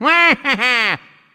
One of Waluigi's voice clips in Mario Party 6